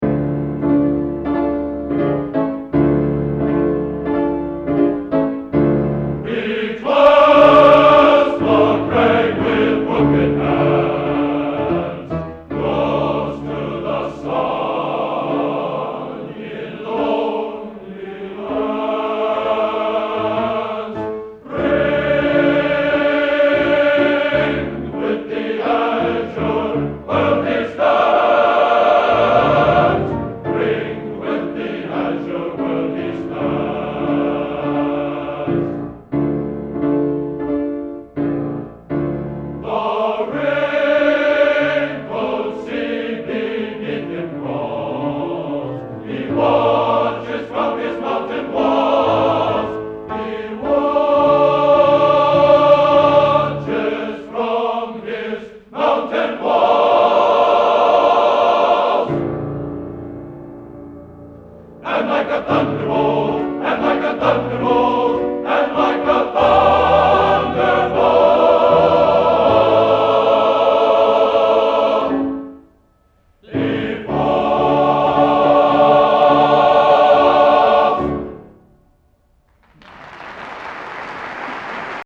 Genre: | Type: End of Season